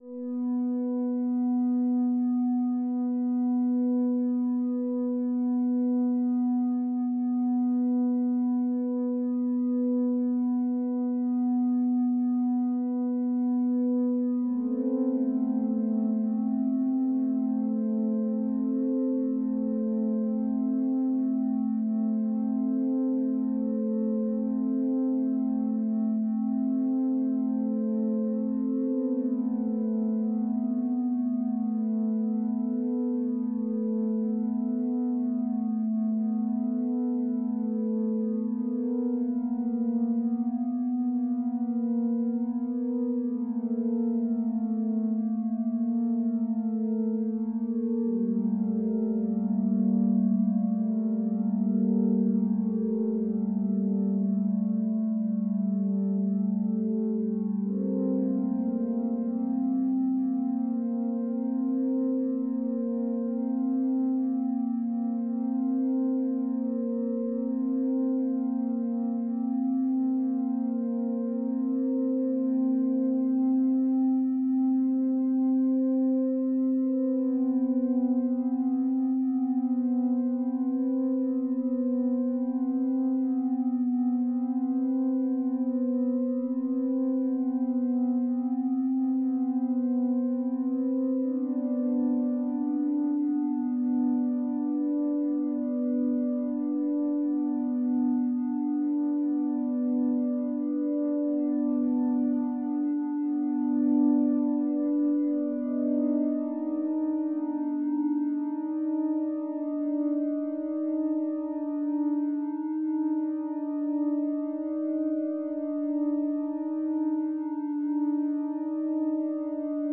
Dissonant Tones
A two part harmony that moves in and out of a dissonant state to create a sense of evolving tension.